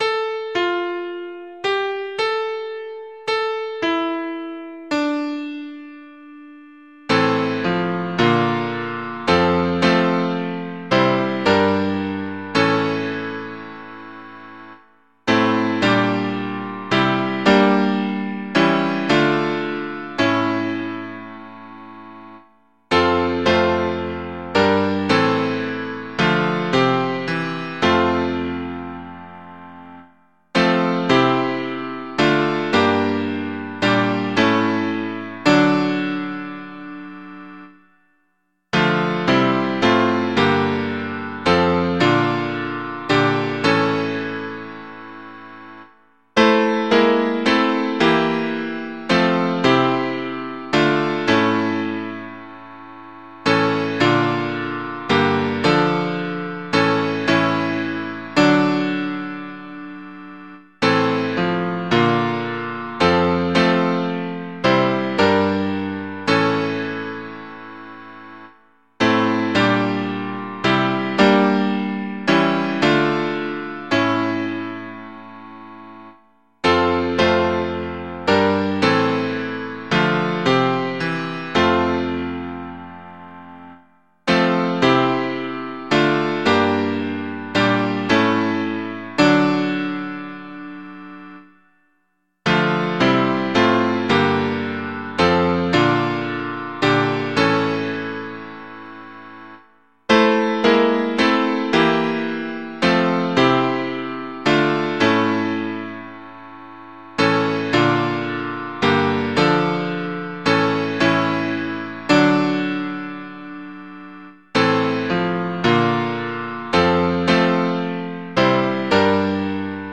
Words: Justus Gesenius, 1646. Translated by William Mercer, 1857.
Music: 'Wenn Mein Sünd' Michael Praetorius, 1609.
Setting: "Mehrstimmiges Choralbuch", 1906.